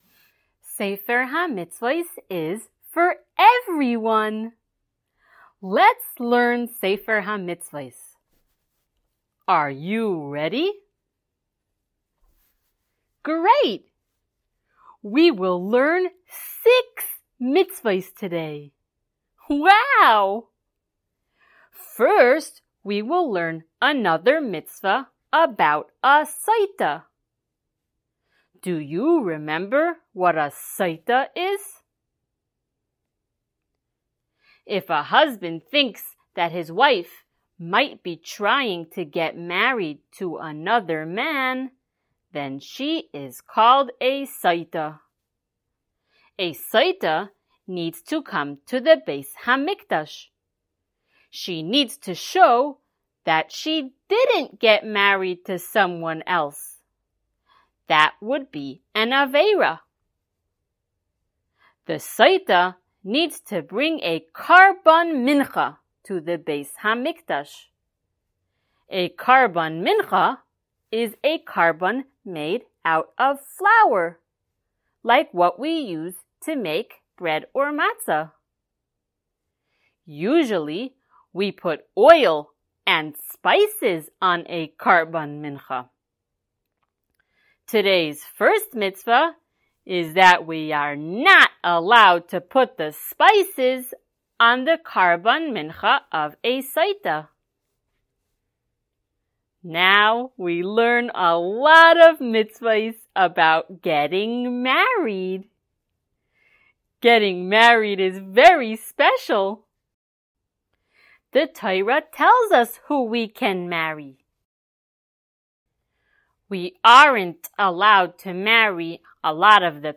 SmallChildren_Shiur087.mp3